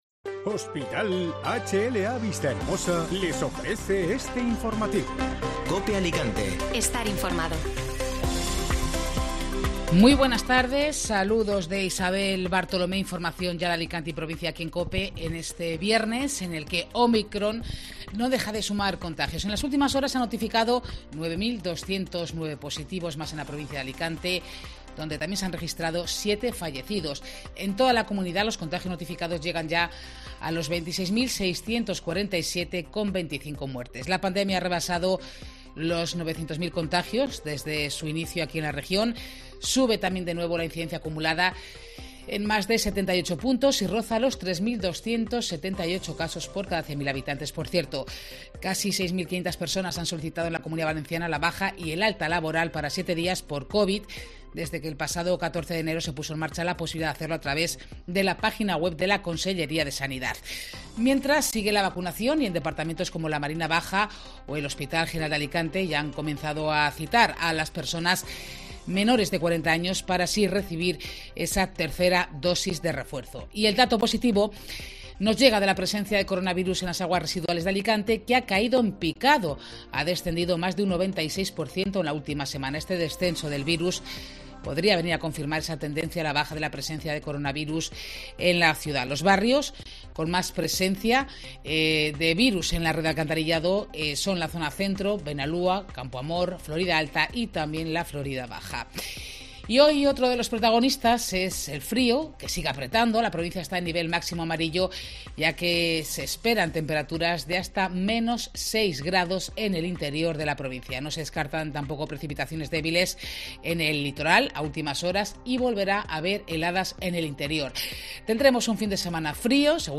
Informativo Mediodía COPE (Viernes 21 de enero)